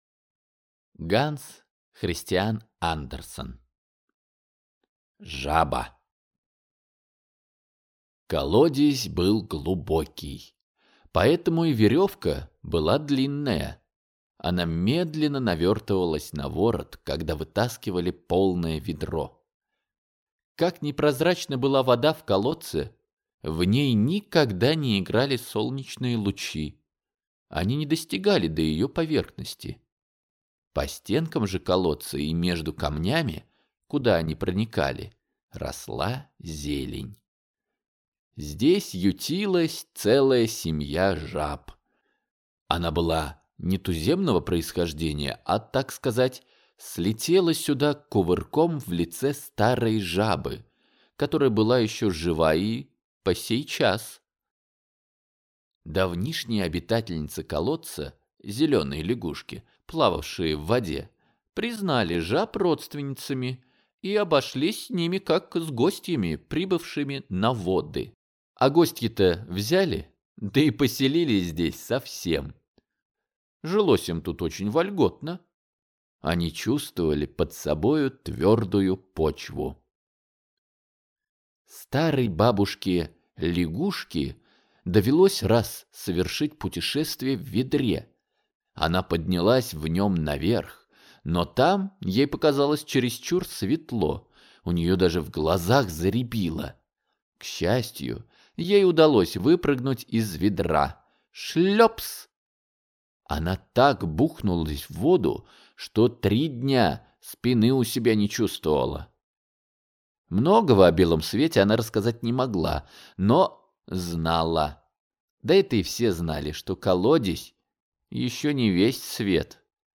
Аудиокнига Жаба | Библиотека аудиокниг
Прослушать и бесплатно скачать фрагмент аудиокниги